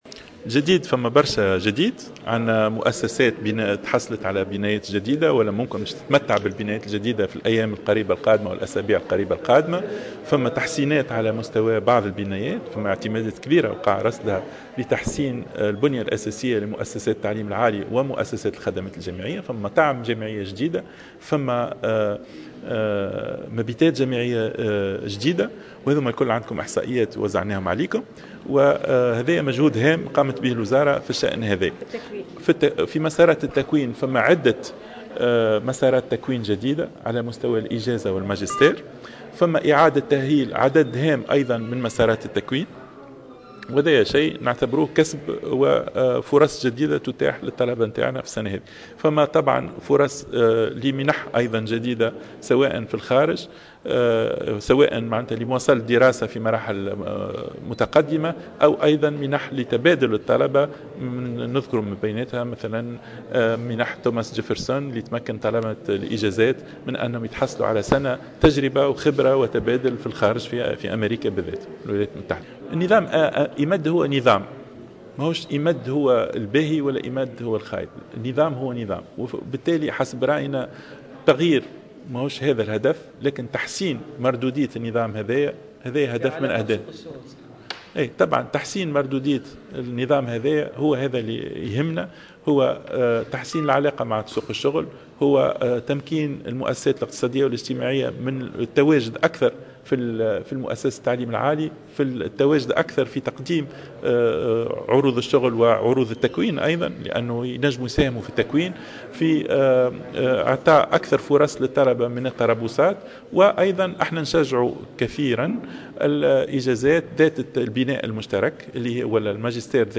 تحدث وزير التعليم العالي والبحث العلمي شهاب بودن لمراسلة جوهرة أف أم عن آخر استعدادات الوزارة للعودة الجامعية.